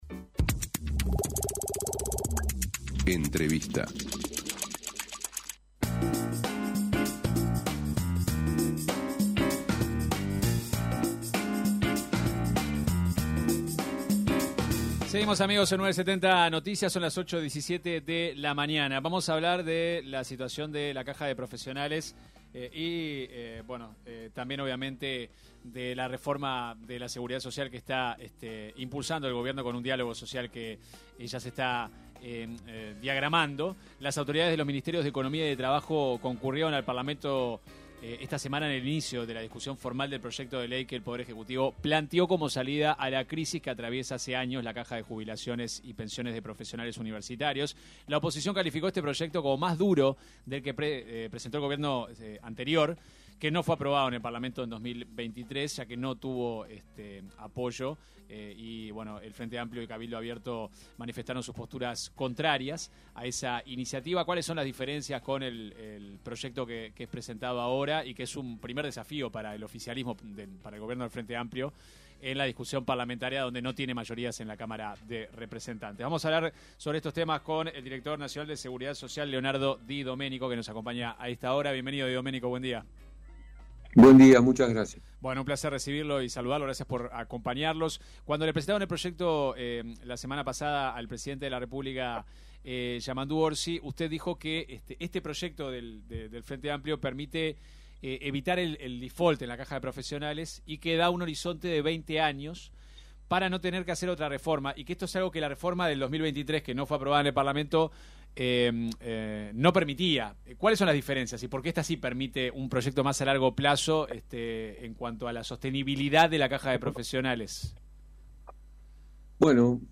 Escuche la entrevista completa aquí: El director Nacional de Seguridad Social, Leonardo Di Doménico, se refirió en una entrevista con 970 Noticias, al proyecto de ley que el Poder Ejecutivo envió al Parlamento para salvar la Caja de Profesionales, y respondió a algunas críticas de la oposición.